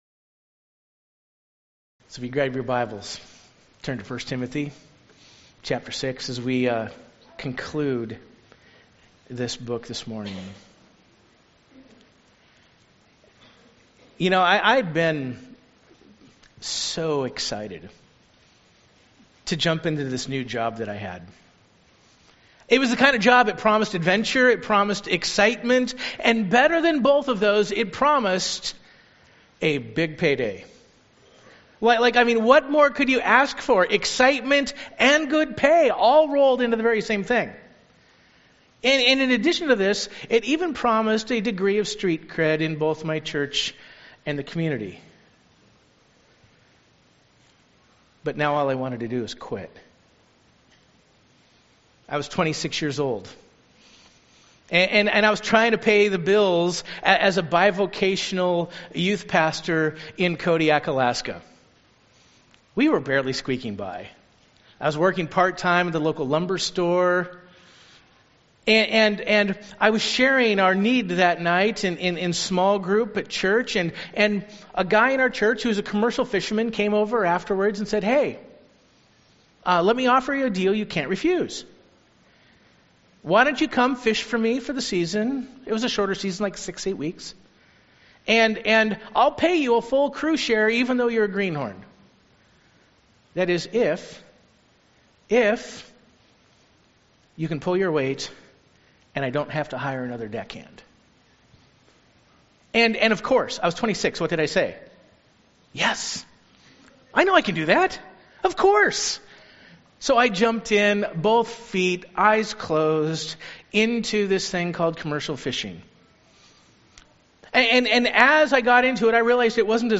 Sermon Outline: I. Know Where to Run (1 Timothy 6:11) A. What to Flee B. What to Pursue II.